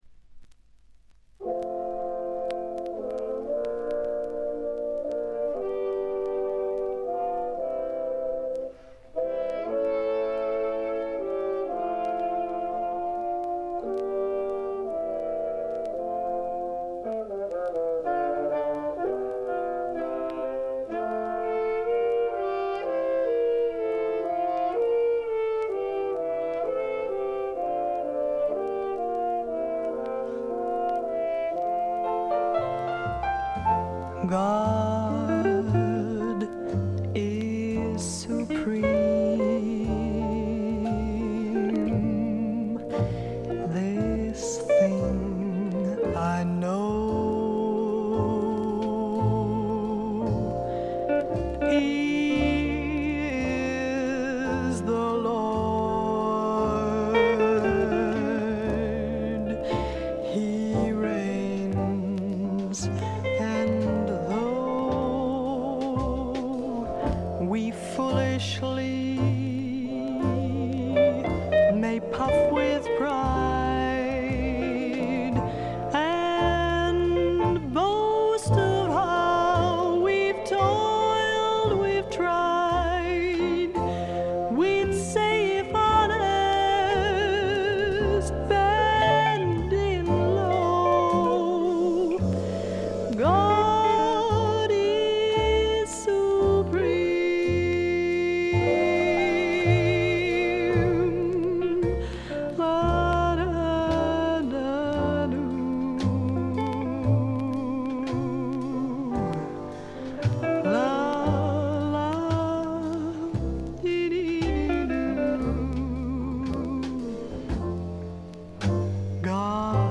プレスが良くないのか見た目より悪くて、全体にバックグラウンドノイズ、チリプチ多め大きめ、散発的なプツ音少々。
存在感のあるアルトヴォイスがとてもいい味をかもし出して、個人的にも大の愛聴盤であります。
試聴曲は現品からの取り込み音源です。